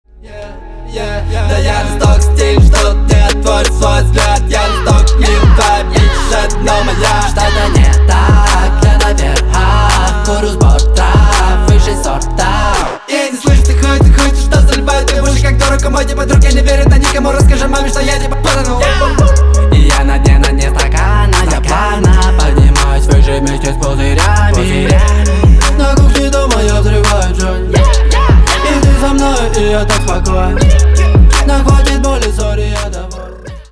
• Качество: 320, Stereo
Хип-хоп
русский рэп
качающие